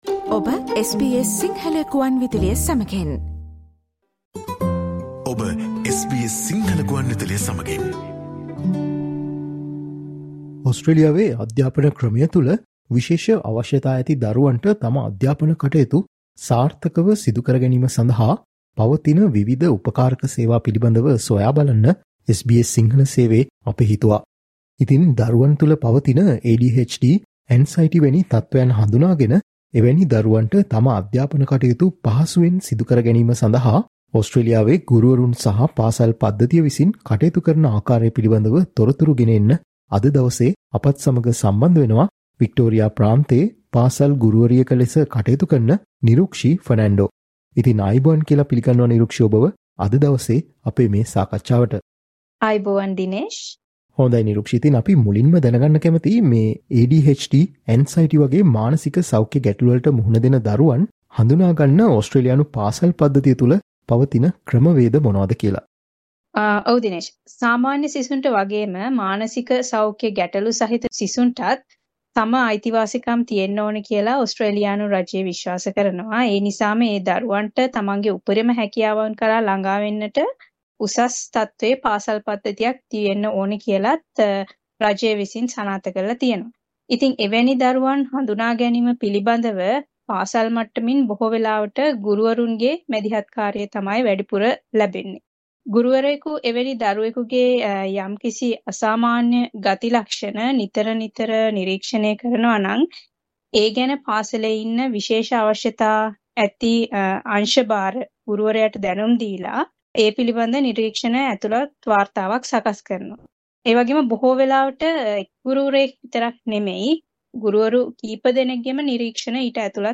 SBS සිංහල සේවය සිදුකළ සාකච්ඡාව.